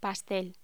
Locución: Pastel
voz